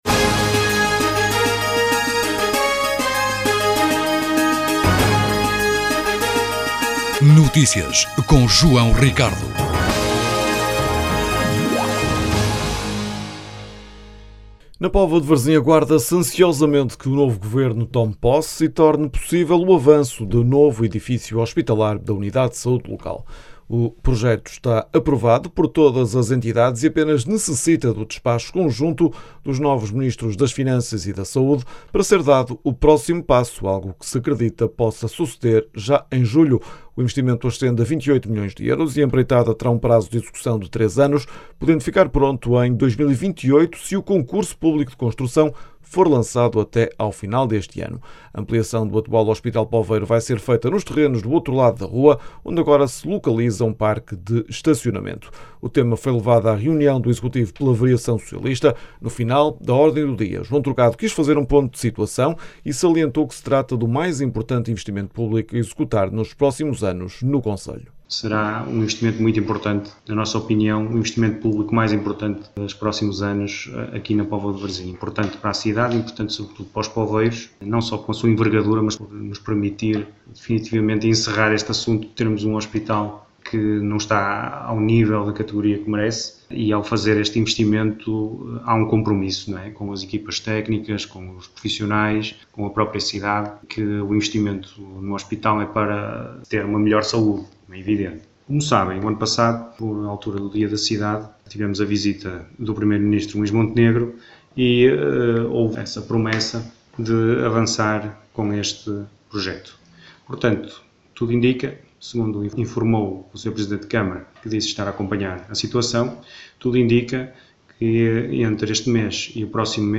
Os esclarecimentos foram dados pelo edil Aires Pereira na sessão que decorreu à porta fechada, mas foi Luís Diamantino a prestar declarações. O vice-presidente recordou que a autarquia já disponibilizou o terreno e tem previsto um cofinanciamento municipal de 1,5 milhões de euros, esperando que finalmente seja possível concretizar esta luta de muitos anos que assegura a continuidade do hospital bem no centro da cidade. As declarações podem ser ouvidas na edição local.